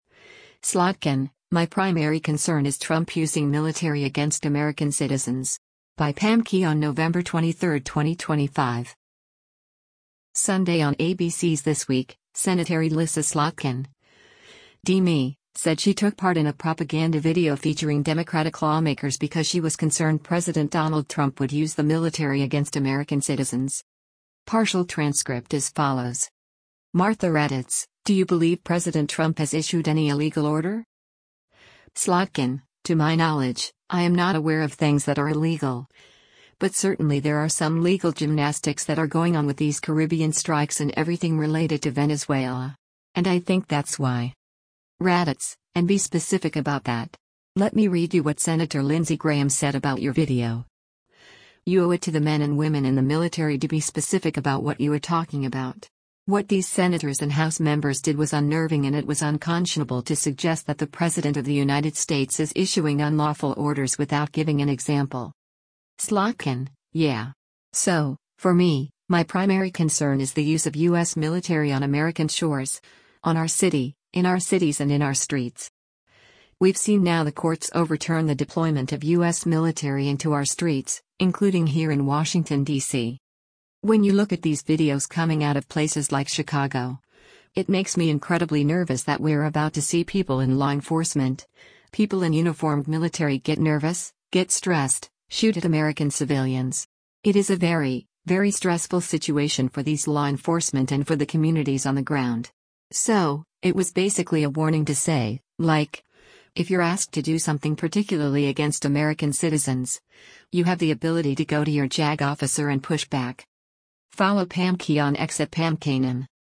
Sunday on ABC’s “This Week,” Sen. Elissa Slotkin (D-MI) said she took part in a propaganda video featuring Democratic lawmakers because she was concerned President Donald Trump would use the military “against American citizens.”